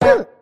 tt_s_ara_cfg_toonHit.mp3